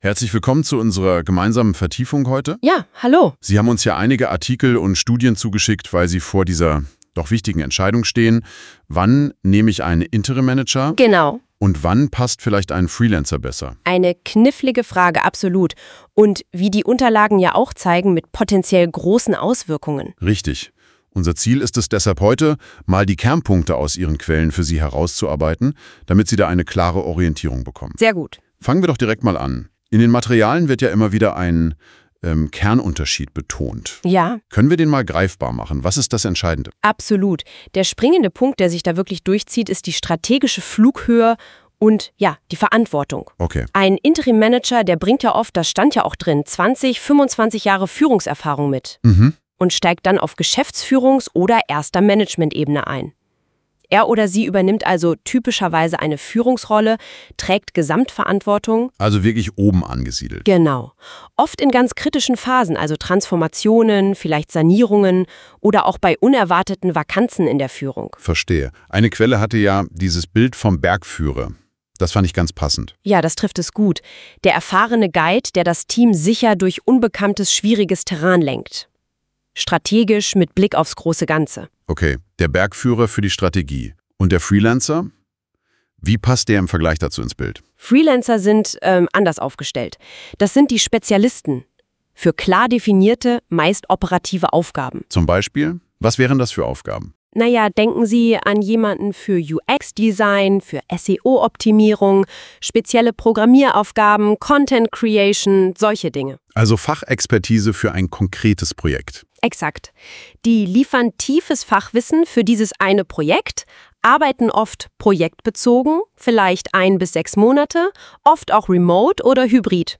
Der Podcast wurde von künstlicher Intelligenz generiert .